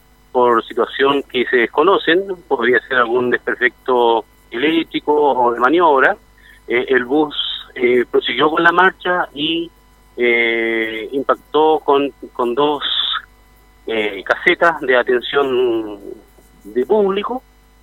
Por su parte, el prefecto de Carabineros de Bío Bío, Hugo Zenteno, detalló que por situaciones que se desconocen la máquina impactó unas oficinas de atención al público.